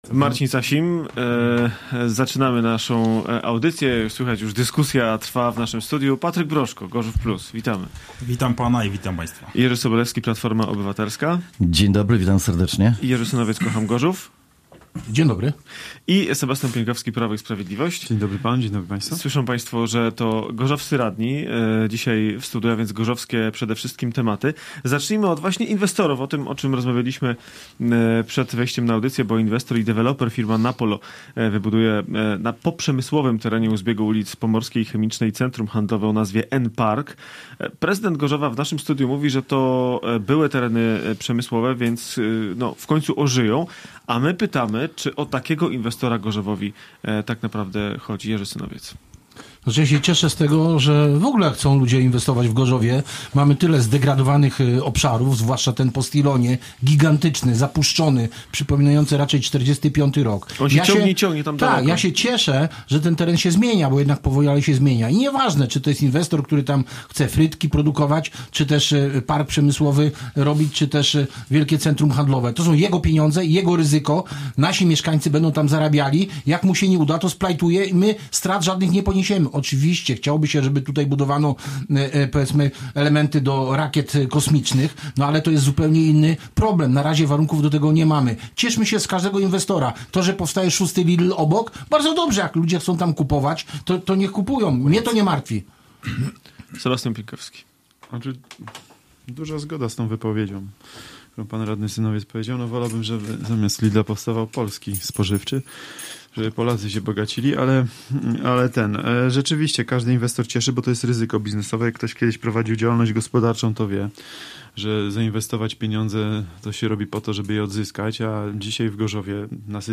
Gośćmi audycji byli radni: Patryk Broszko – Gorzów Plus; Jerzy Sobolewski – PO; Jerzy Synowiec – Kocham Gorzów; Sebastian Pieńkowski – PiS.